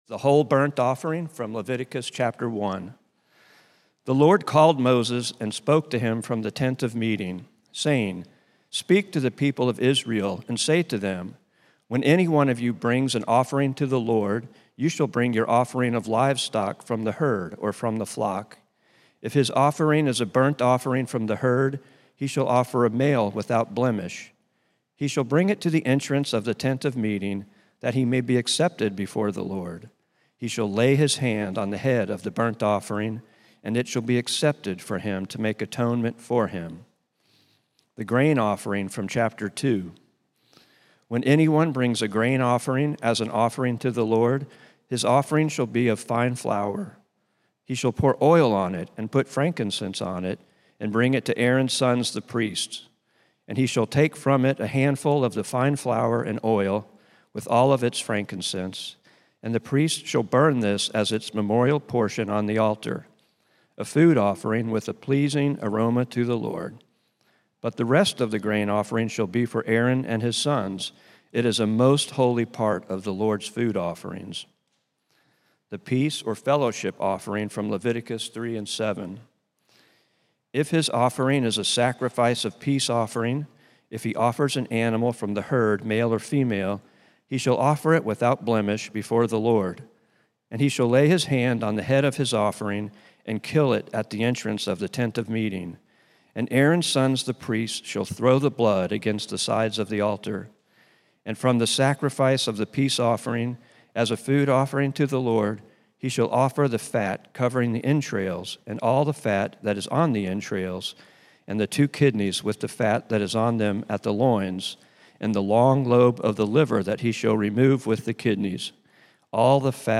A message from the series "Holy God Holy People."